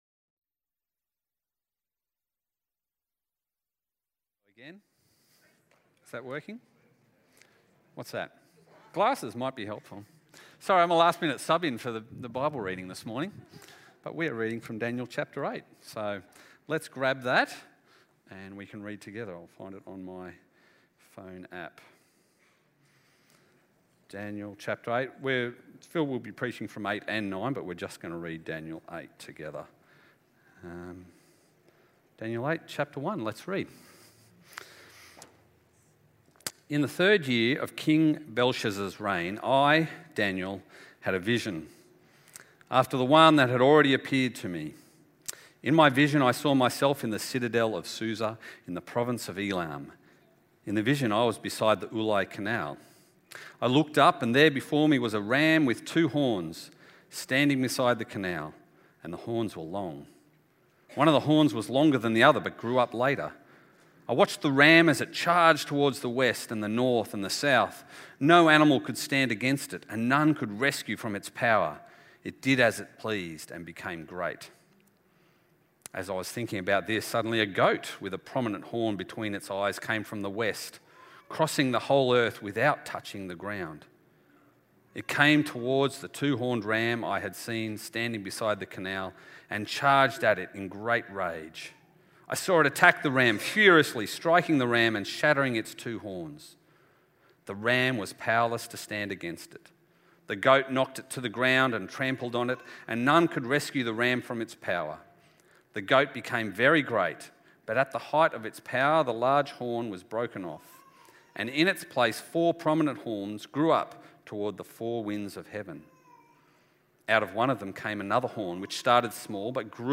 Talk-Faithful-Exiles-13-06.mp3